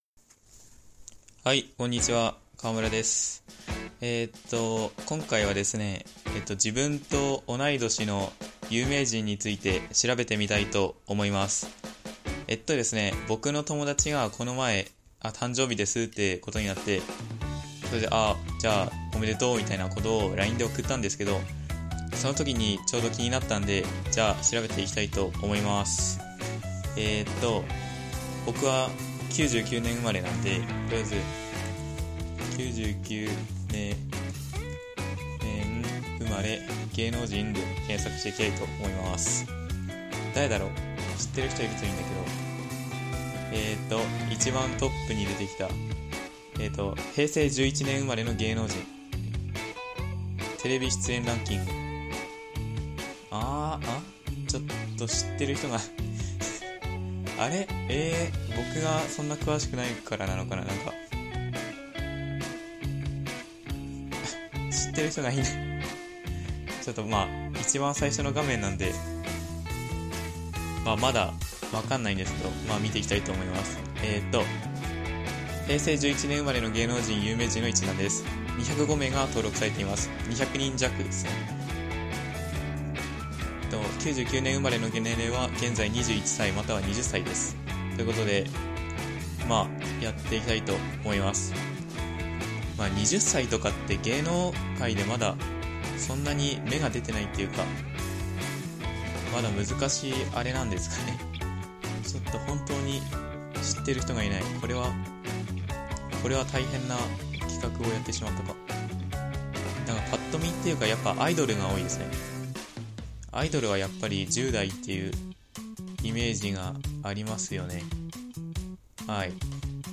力を抜いて聴いてください。力を抜いて話してます。